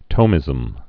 (tōmĭzəm)